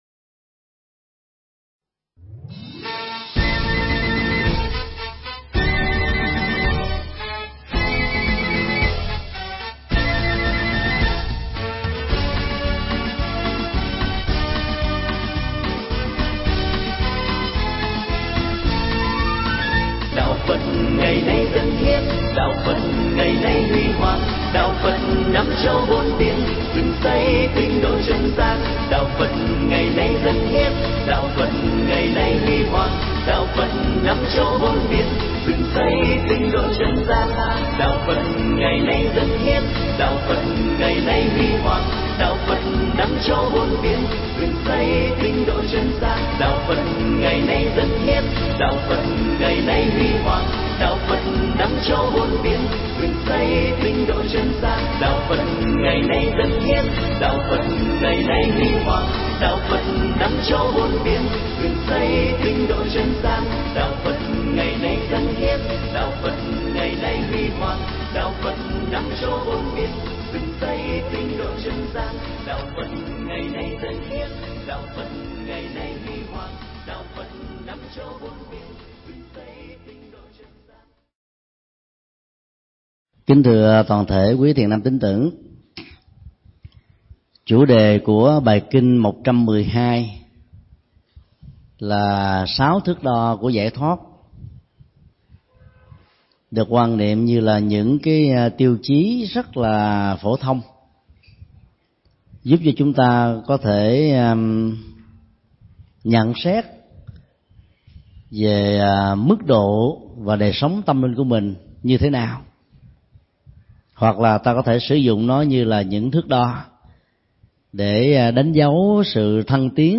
Mp3 Pháp thoại Kinh Trung Bộ 112 (Kinh Sáu Thanh Tịnh) – Sáu thước đo giải thoát do thầy Thích Nhật Từ Giảng tại Chùa Xá Lợi, ngày 11 năm 10 năm 2008